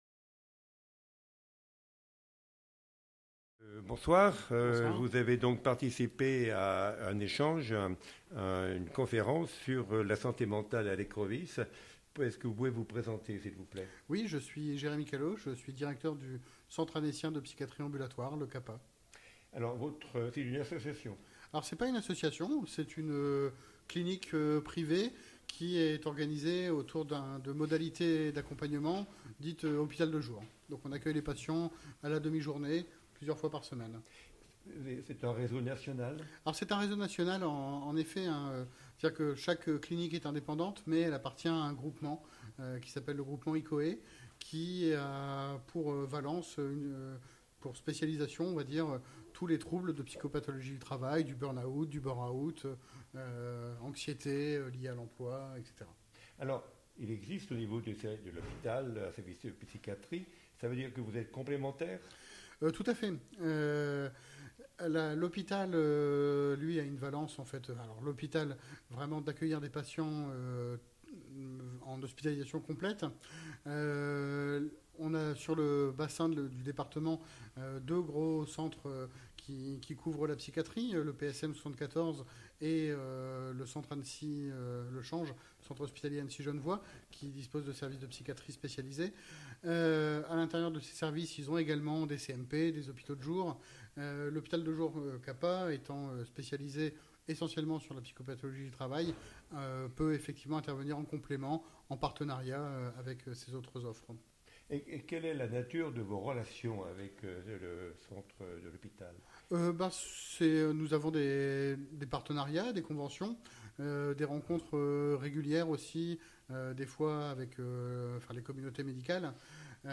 Interview des intervenants à la journée Santé mentale à l’Écrevis